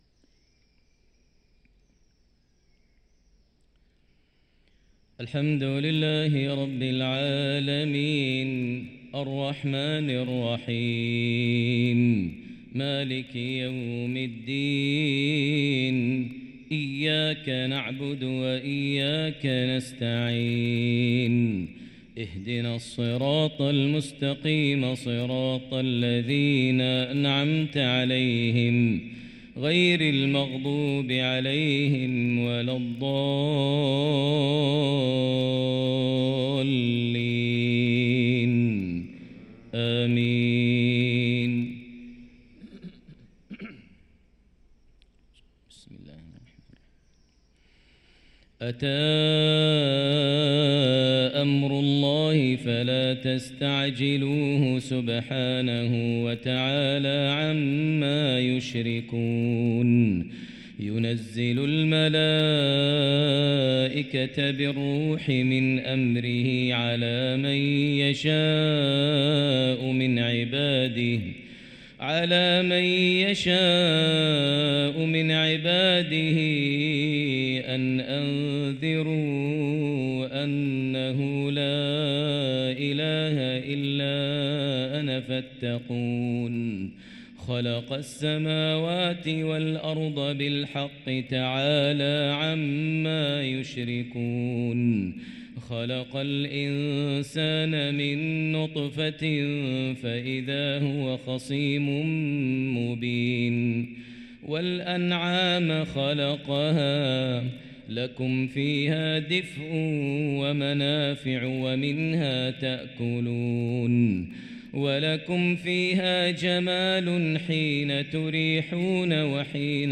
صلاة الفجر للقارئ ماهر المعيقلي 16 رجب 1445 هـ